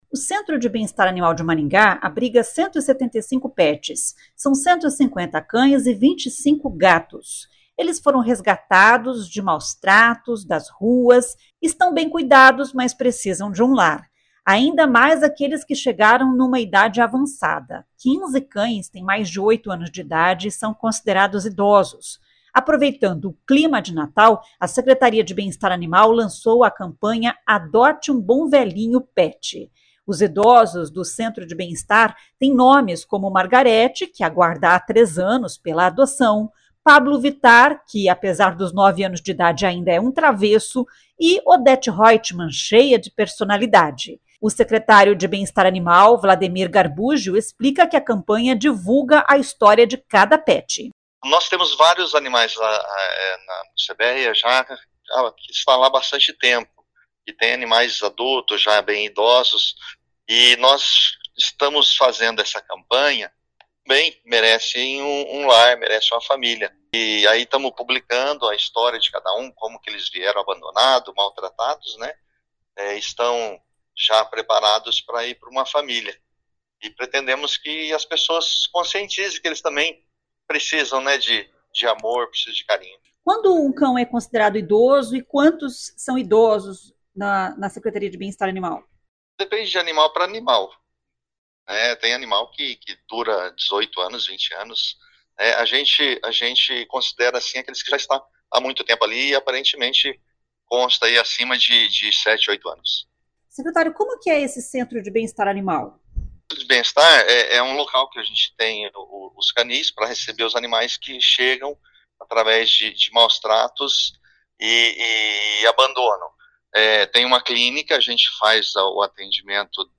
O secretário de Bem-Estar Animal, Wlademir Garbuggio, explica que a campanha divulga a história de cada pet. [ouça o áudio]